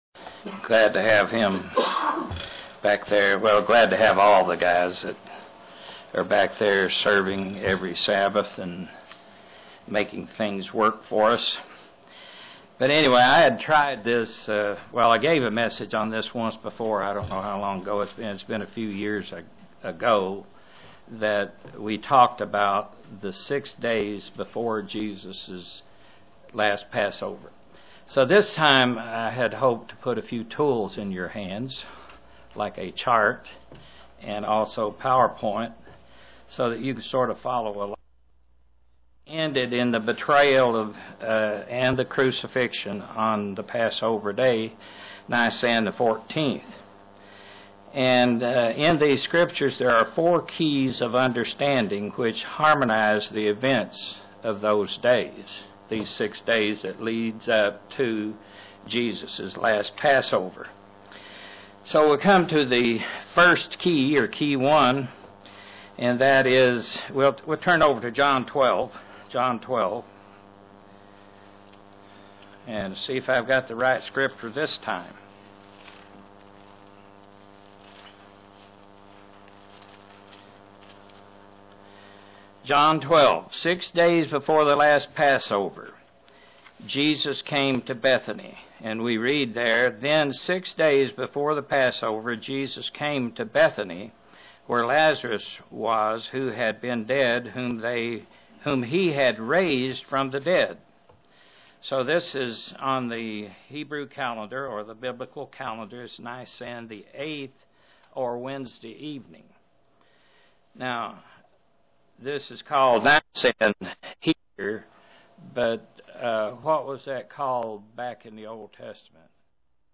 An in-depth explanation of the events leading up to the final Passover Jesus kept with His disciples (Presented to the London KY, Church)